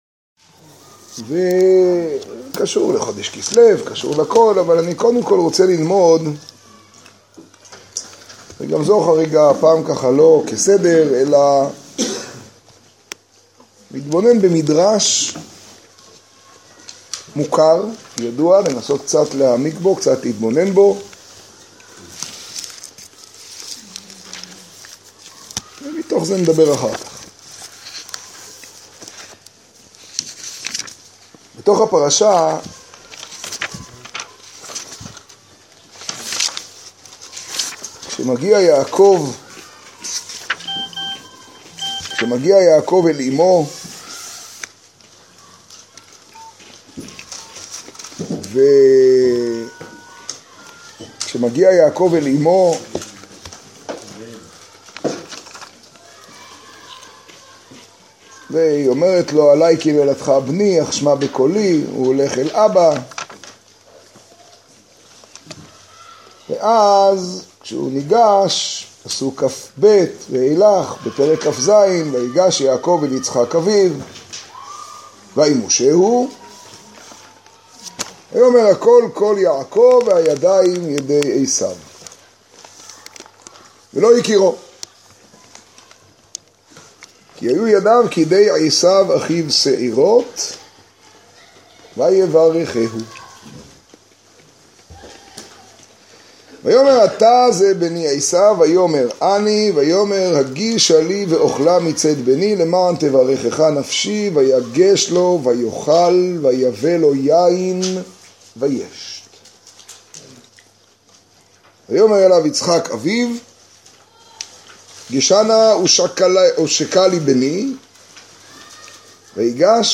השיעור במגדל, פרשת תולדות תשעה.